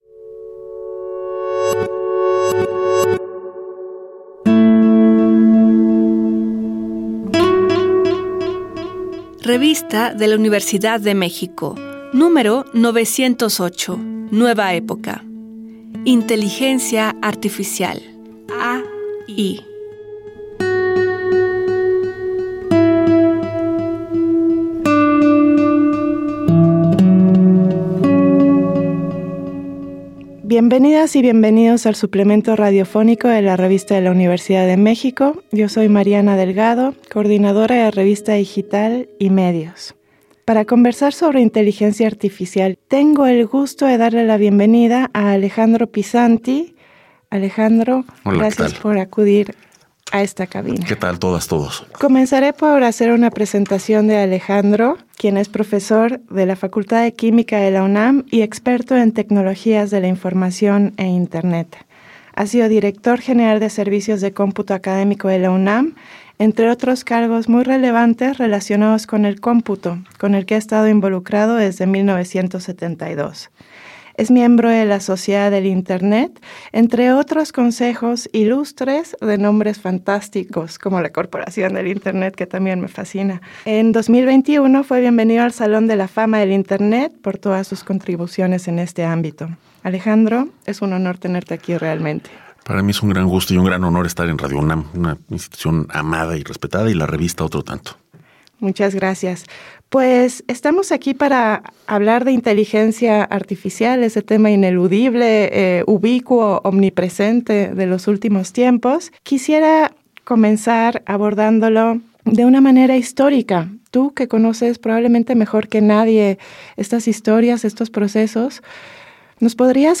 Cargar audio Este programa es una coproducción de la Revista de la Universidad de México y Radio UNAM. Fue transmitido el jueves 9 de mayo de 2024 por el 96.1 FM.